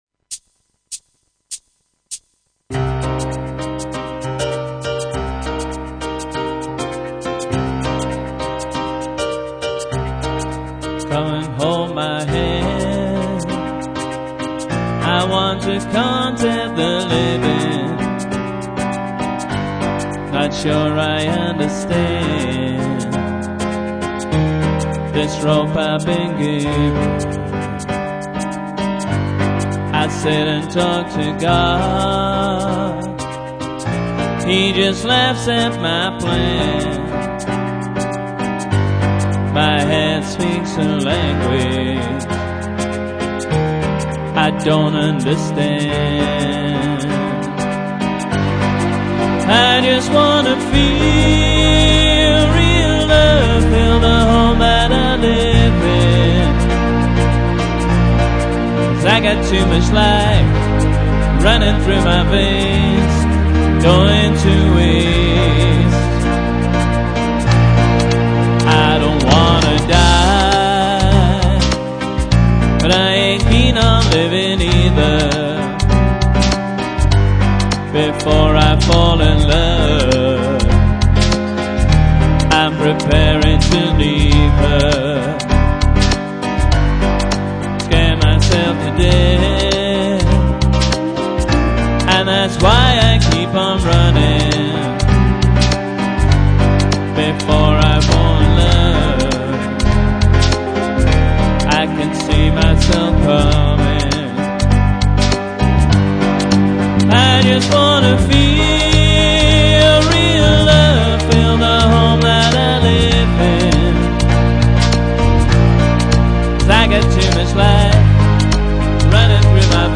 Backing track plus Roland RD700 piano & vocals